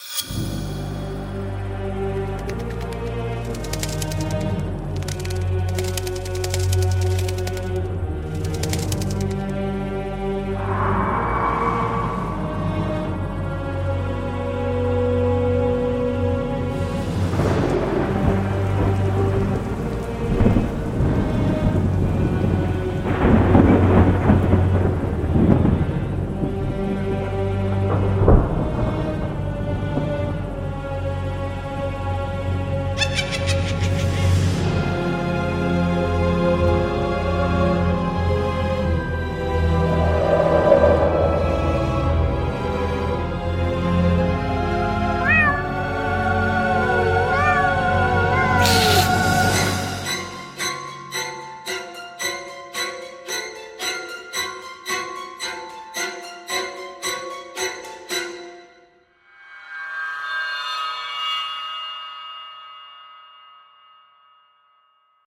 MIguyrZgeZE_Castillo-Embrujado---Música-de-Halloween---Sonidos-de-Truenos---Miedo-128-kbps-.mp3